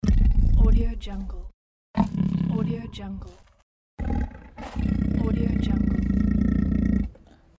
Lion Grumble Bouton sonore
The Lion Grumble is a popular audio clip perfect for your soundboard, content creation, and entertainment.